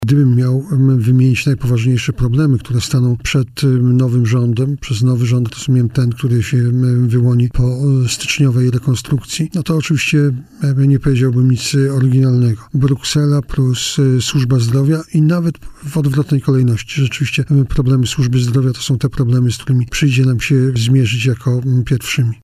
– Kwestie służby zdrowia z pewnością będą jednym z najważniejszych tematów w polskiej polityce w 2018 roku – podkreślił w poranku „Siódma9” wicepremier, minister nauki i szkolnictwa wyższego Jarosław Gowin.